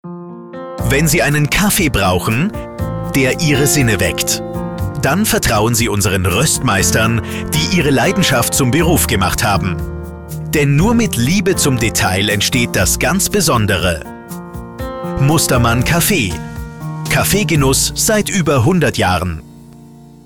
Voice Clone
Radiowerbespot 01
Radiospot "Kaffee"
Immer fällt eine gewisse Monotonie auf, die Betonungen wirken oft übertrieben und künstlich.